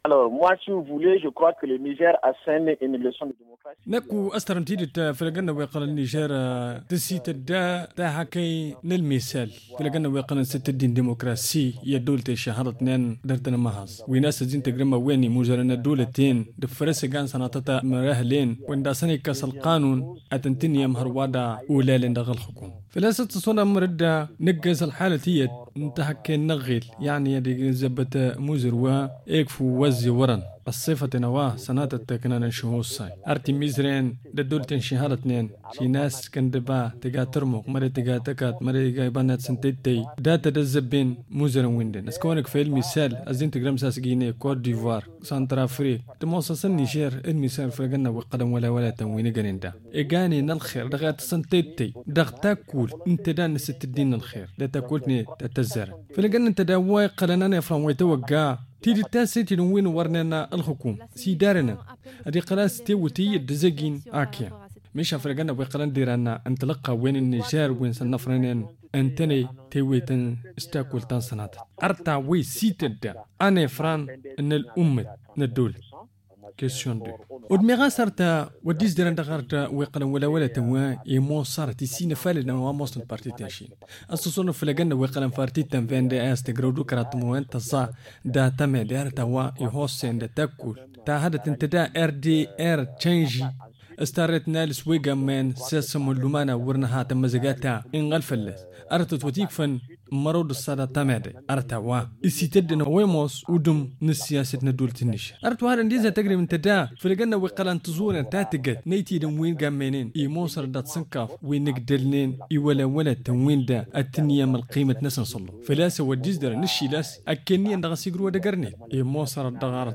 Il est joint au téléphone